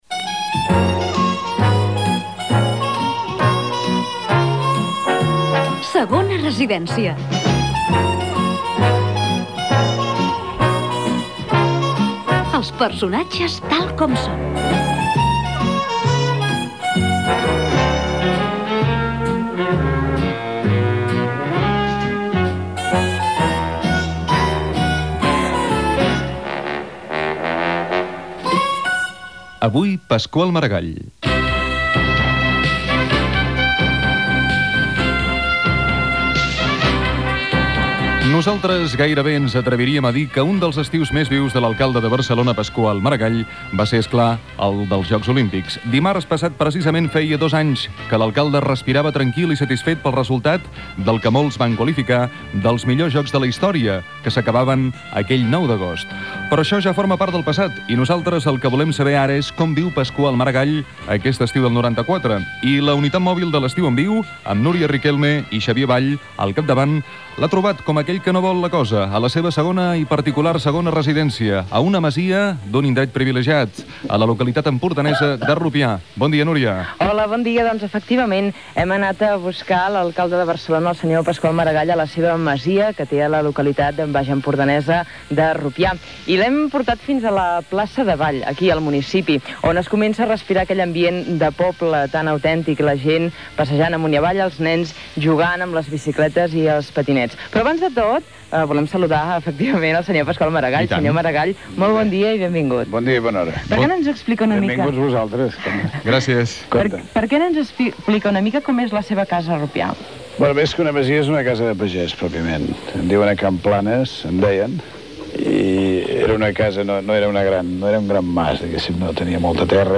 "Segona residència". Careta de la secció, presentació i entrevista a l'alcalde de Barcelona Pasqual Maragall a la vila de Rupià
Entreteniment